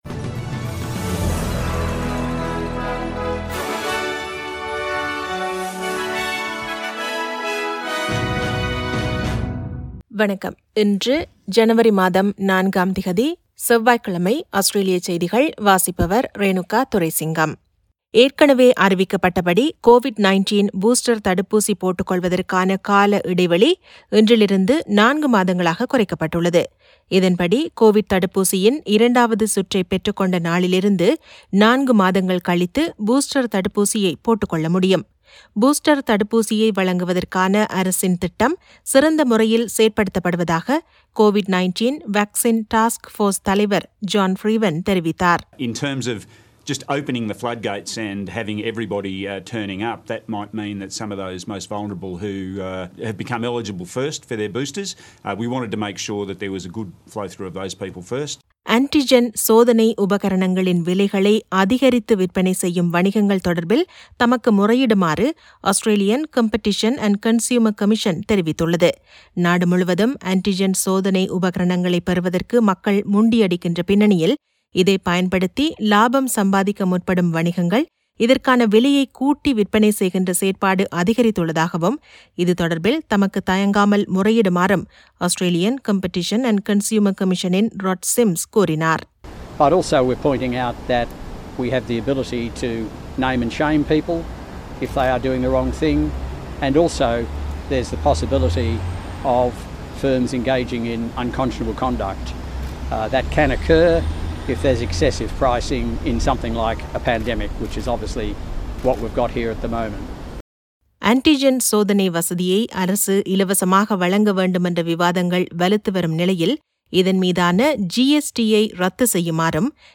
Australian news bulletin for Tuesday 4 Jan 2022.